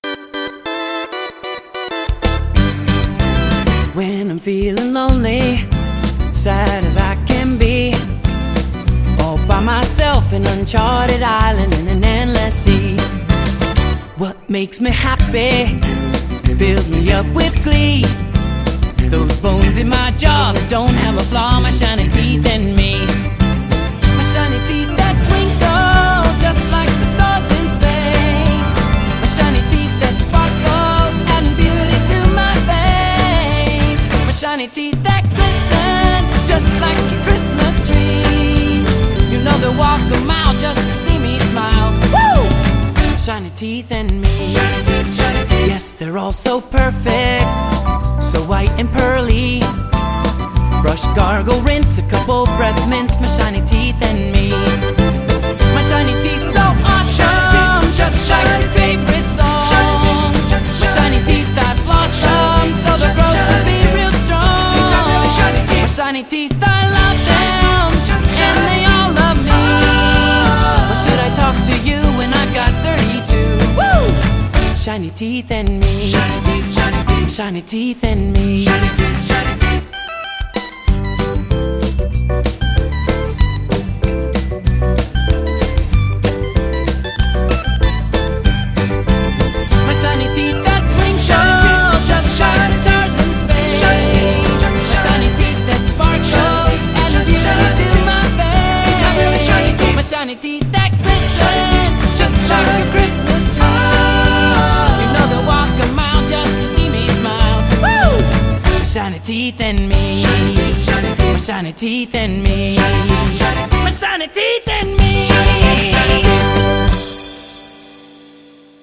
BPM190-190
Audio QualityCut From Video